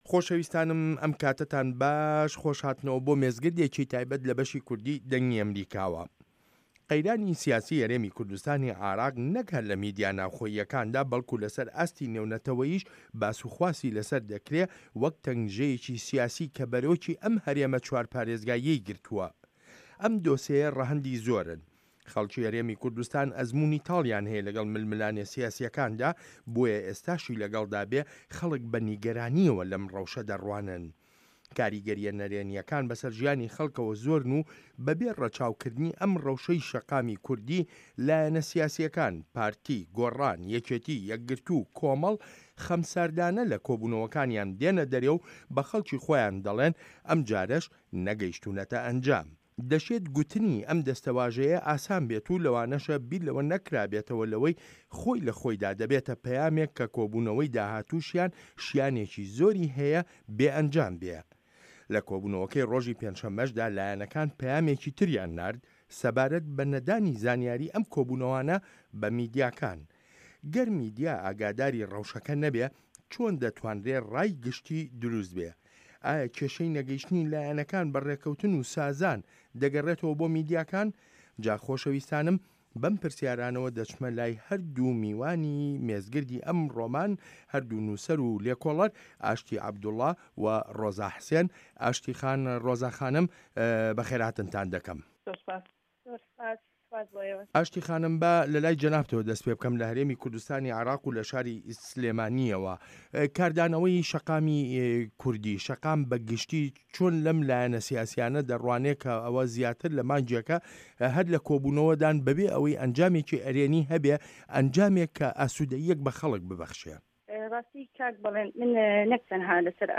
مێزگرد: میدیا و ڕەوشی خەڵک و قەیرانی سیاسی هەرێم